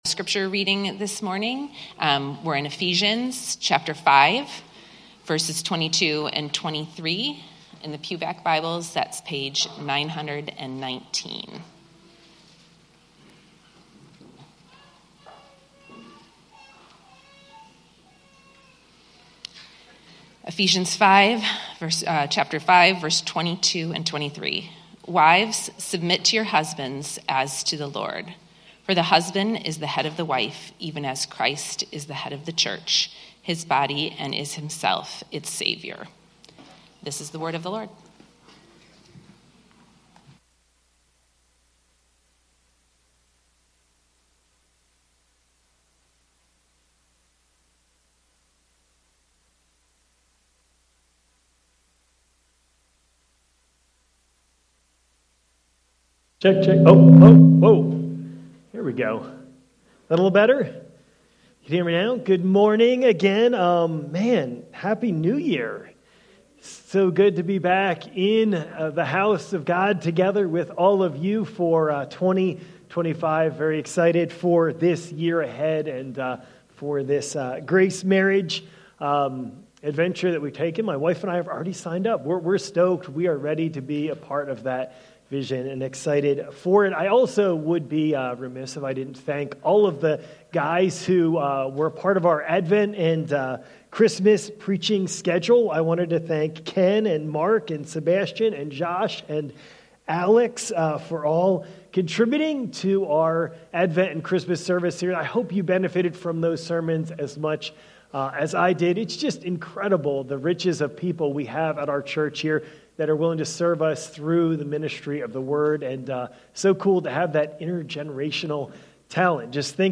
Grace Marriage Vision Sermon